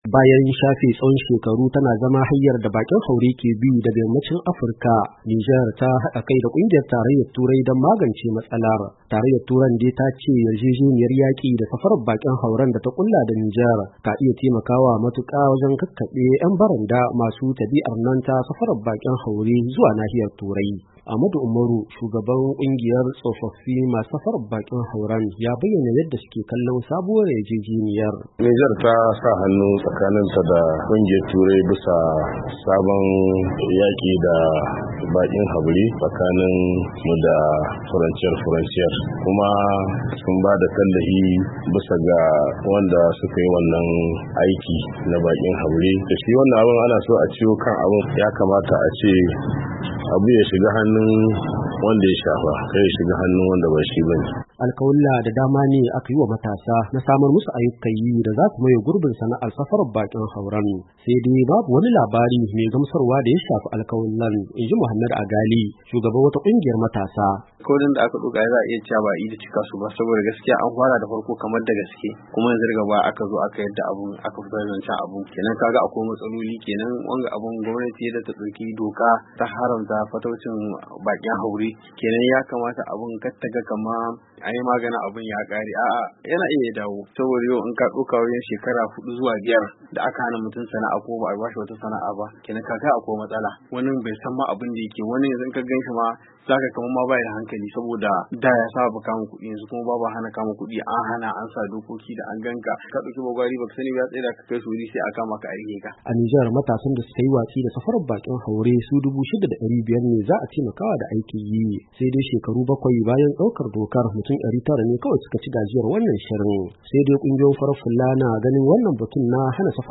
RAHOTO YARJEJENIYA NIJAR DA TARAYYAR TURAI.mp3